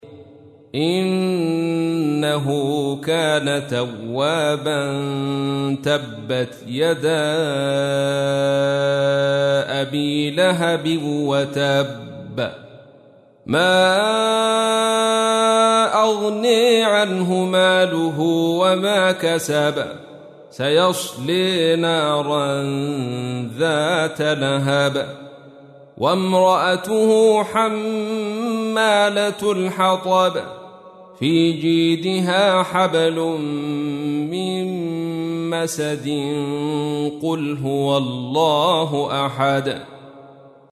تحميل : 111. سورة المسد / القارئ عبد الرشيد صوفي / القرآن الكريم / موقع يا حسين